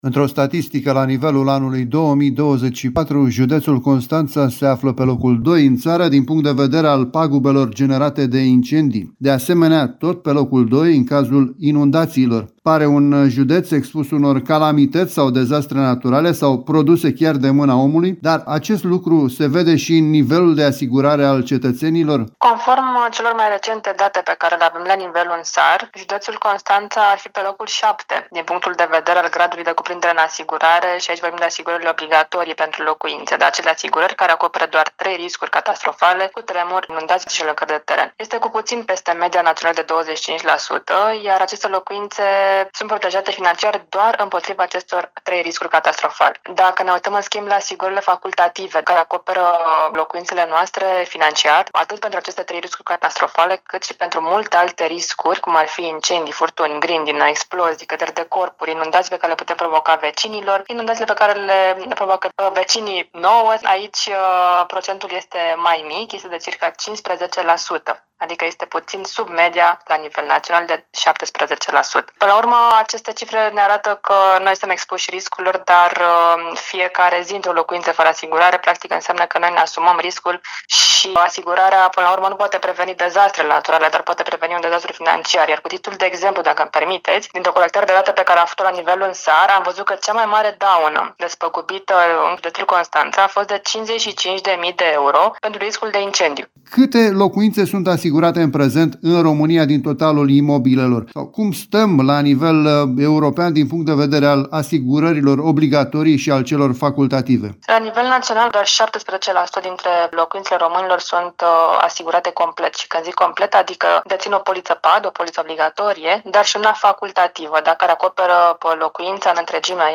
consultant în asigurări.